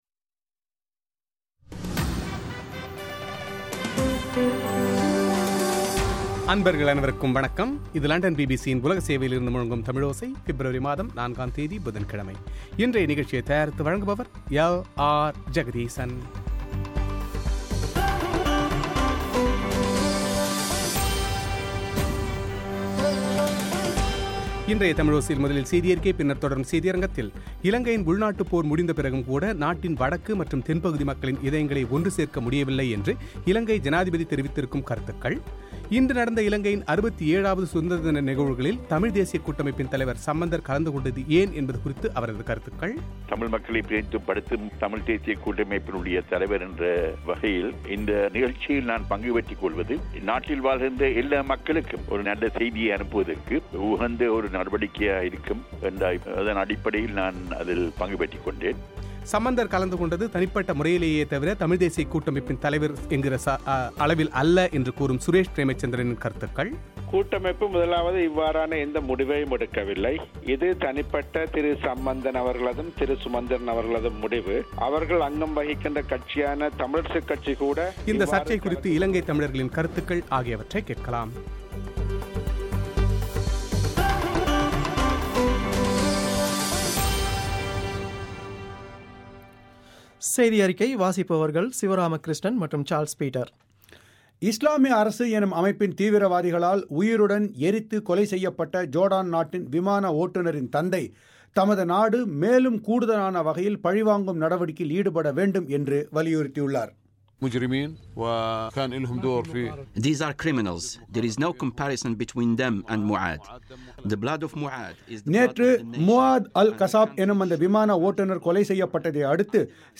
சுமார் 40 ஆண்டுகளுக்குப்பிறகு இன்று நடந்த இலங்கையின் 67ஆவது சுதந்திர தின நிகழ்வுகளில் தமிழ்த்தேசிய கூட்டமைப்பின் தலைவர் சம்பந்தர் கலந்துகொண்டது ஏன் என்பது குறித்து அவரது செவ்வி;